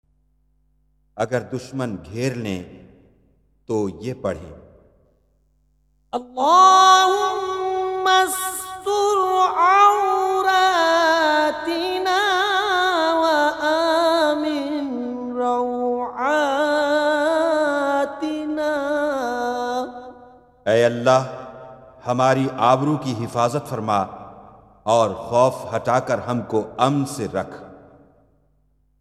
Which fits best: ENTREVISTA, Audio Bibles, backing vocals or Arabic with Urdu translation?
Arabic with Urdu translation